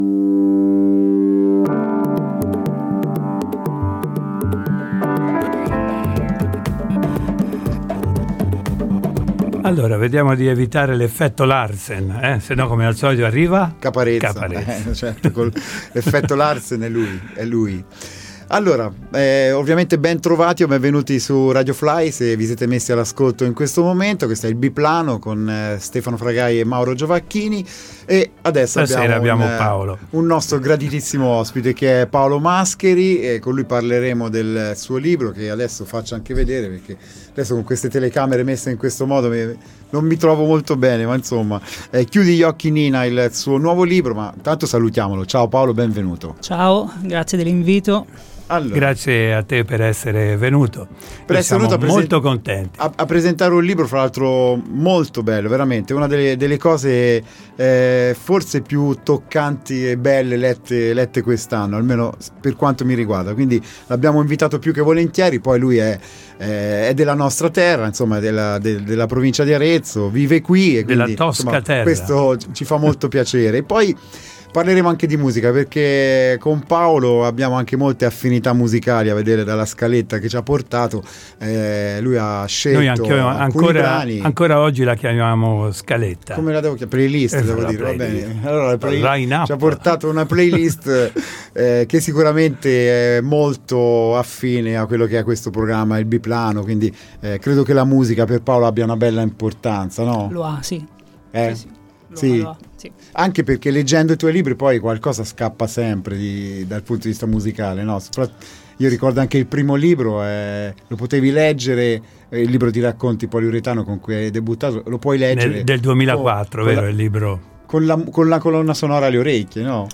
Ospite in studio